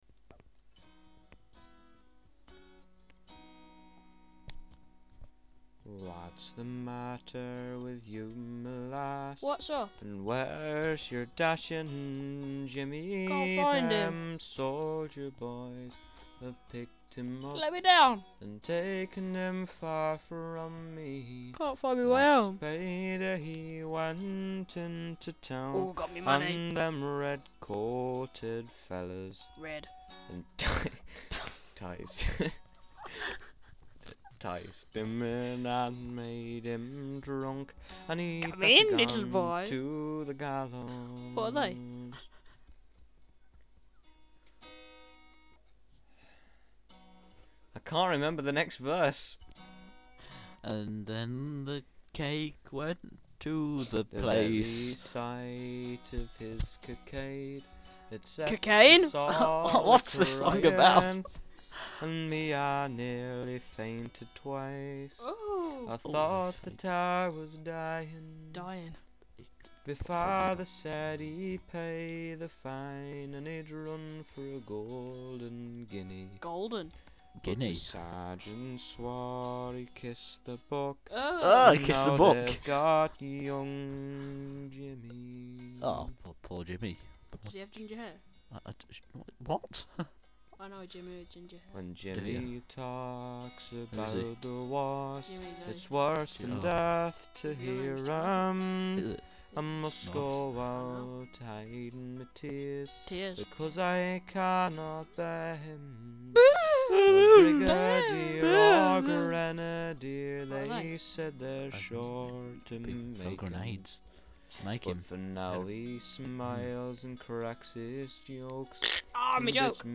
We are back, this time even more unprofessional and unorganised than before. With more rambling, charmless, idle chit-chat, and our inane competitions, 'What's in the House?' and 'Which Roman State are we Thinking Of?'